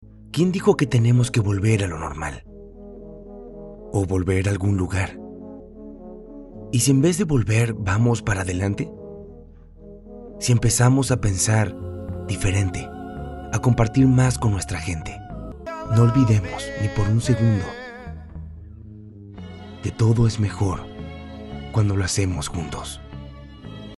Distintas tonalidades y estilos de voz
Español Neutro
Inspiradora / Motivacional
inspiradora-motivacional.mp3